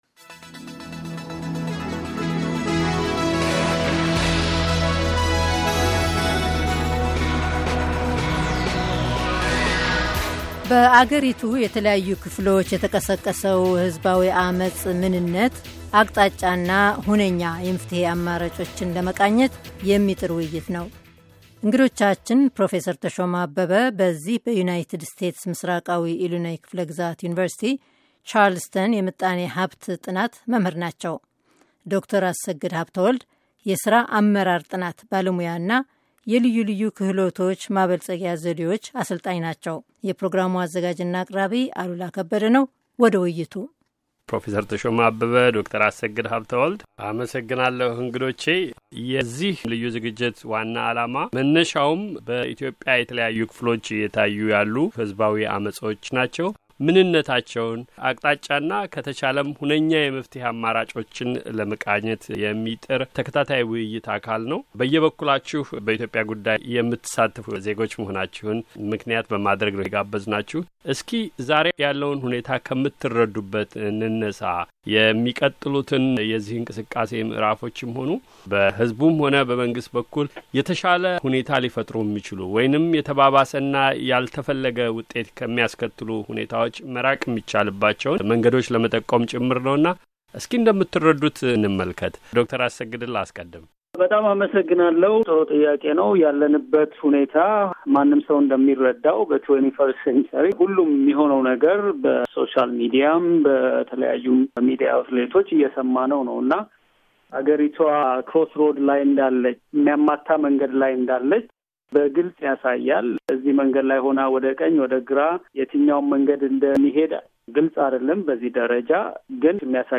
ልዩ ውይይት:- በኢትዮጵያ ሰሞንኛ ጉዳዮች